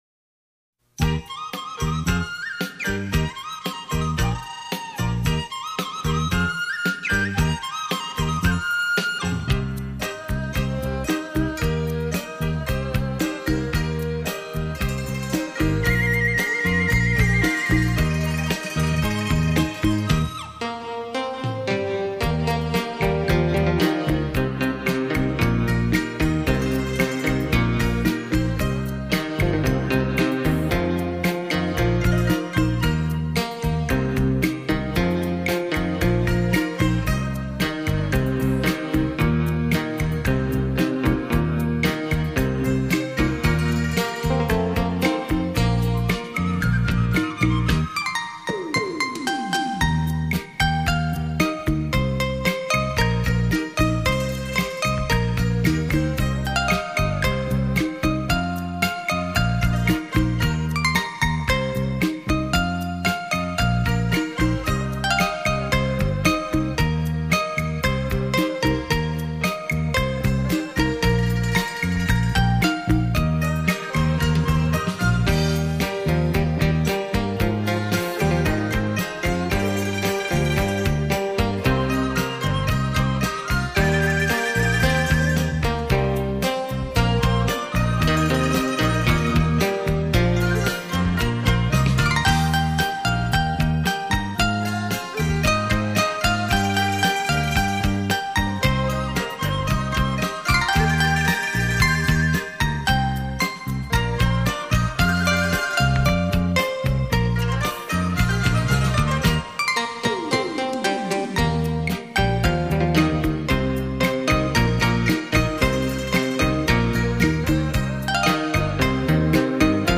笙·梆笛·二胡·洋琴·琵琶·女合声·西乐大合奏
绕场立体音效 发烧音乐重炫
唯美女合声 典雅怡情